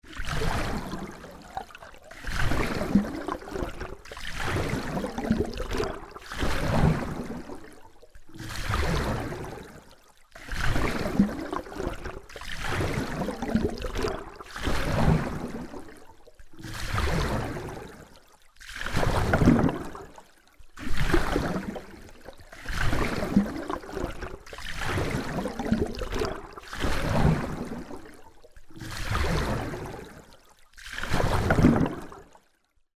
Звуки весла
На этой странице собраны звуки весла: плеск воды, ритмичные гребки и другие умиротворяющие аудиоэффекты.